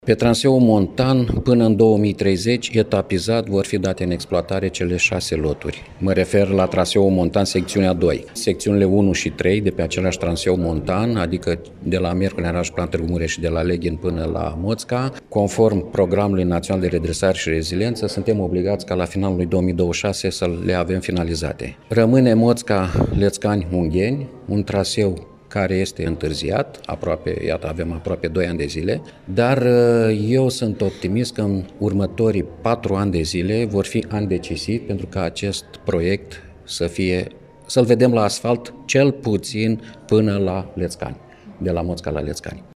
Astăzi, într-o conferință de presă, susținută la Iași,